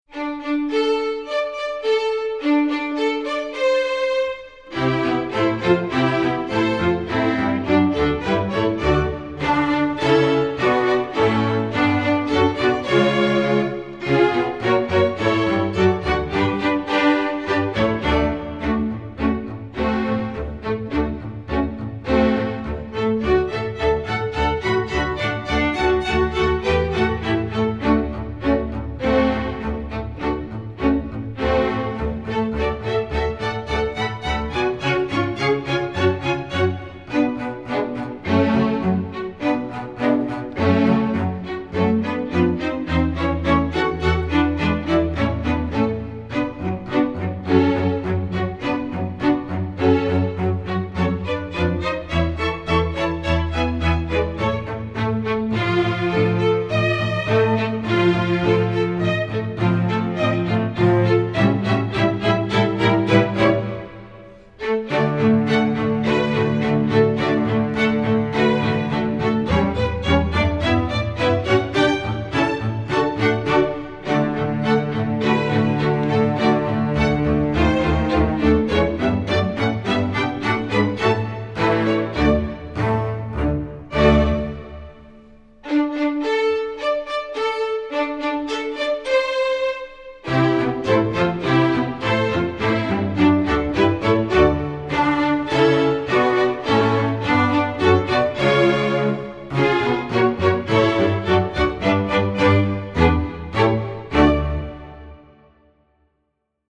Digital sheet music for string orchestra
classical